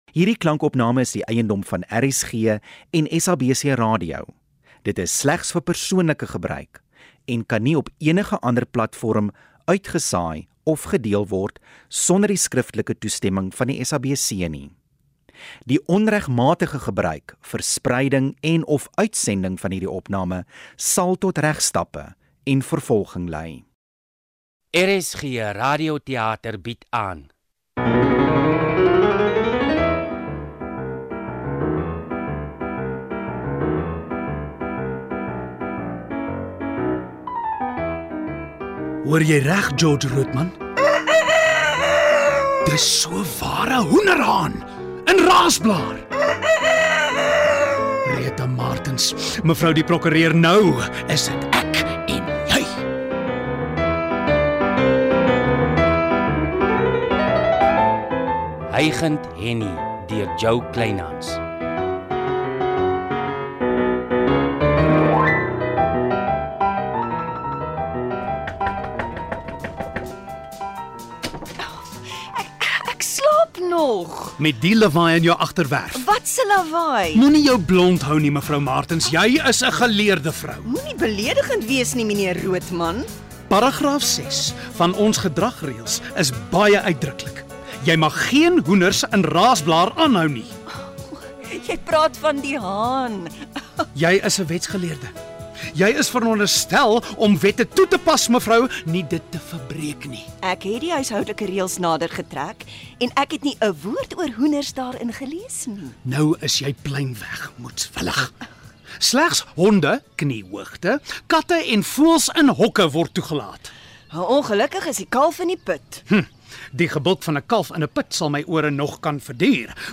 Hygend Hennie is ‘n drama oor ‘n hoenderhaan - RSGplus